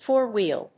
アクセントfóur‐whèel
four-wheel.mp3